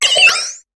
Cri de Tiplouf dans Pokémon HOME.